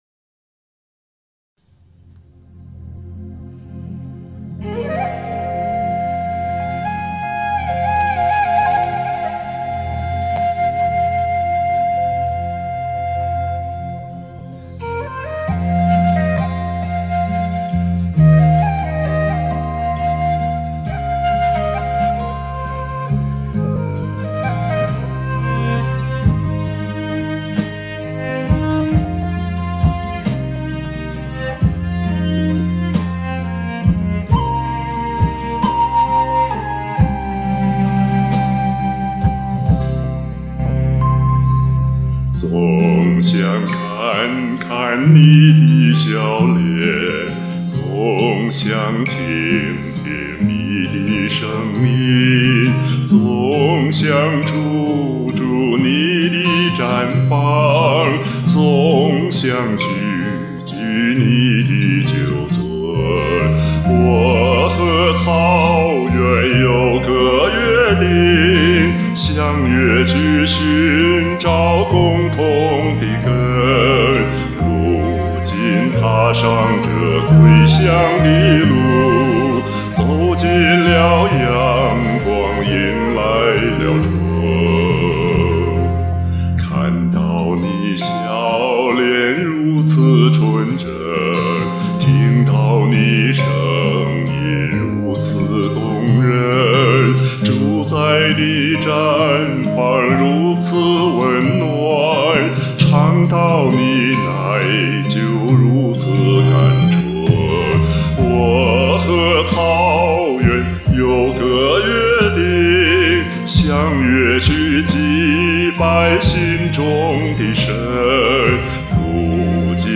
看来很少唱这么快的歌。
自我点评：1.调还是偏低。2.勉强跟上节奏，别的还谈不上。